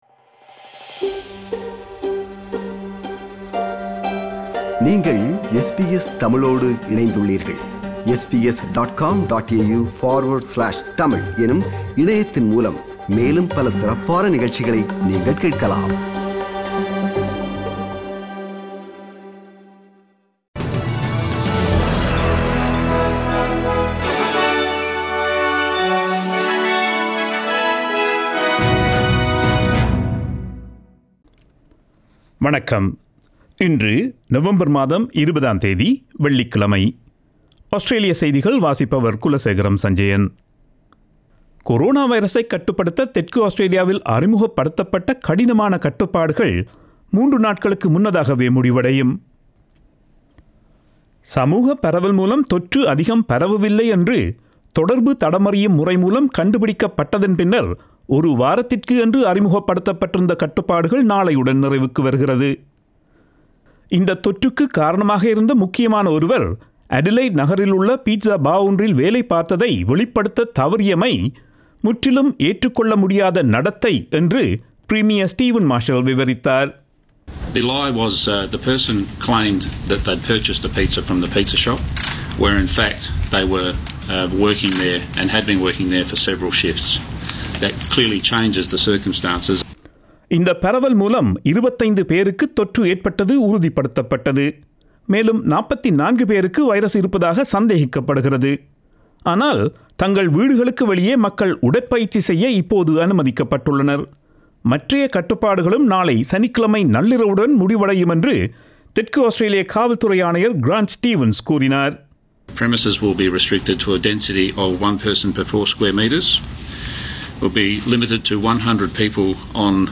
Australian news bulletin for Friday 20 November 2020.